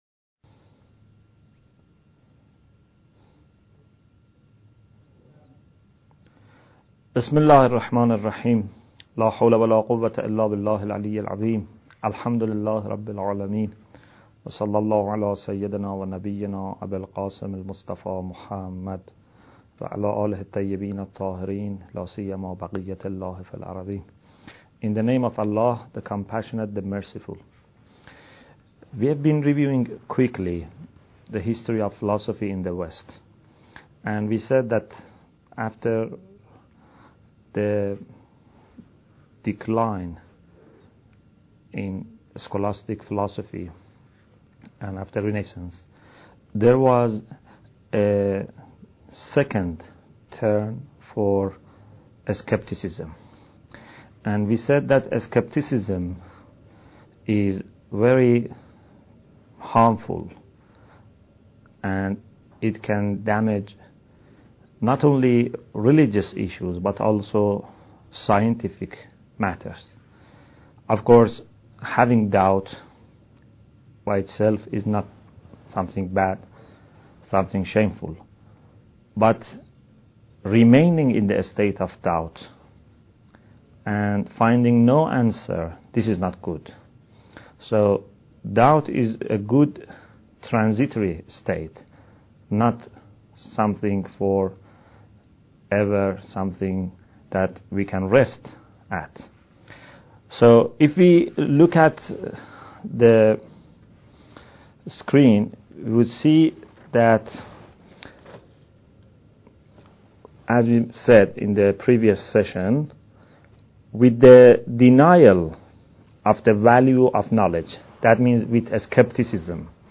Bidayat Al Hikmah Lecture 5